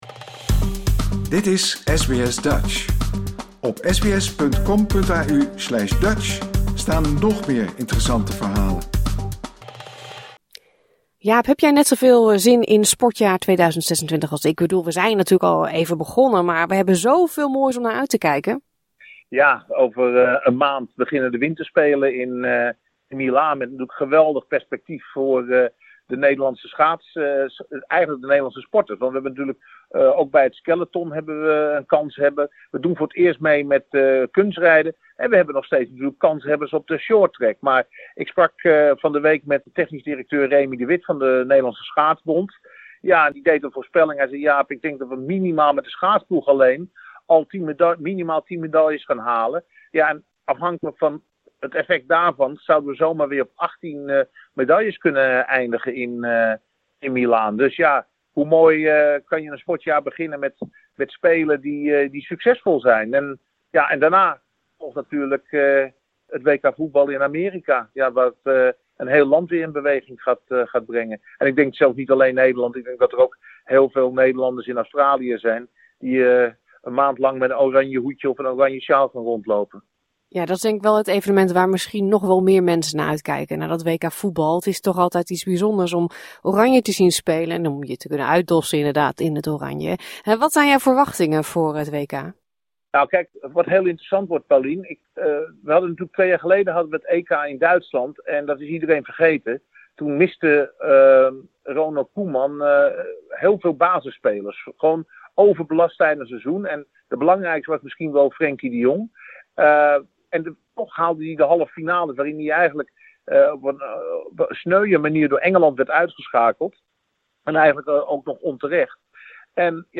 De Olympische Winterspelen, het WK Voetbal en de laatste F1 GP op het circuit van Zandvoort. Sportjournalist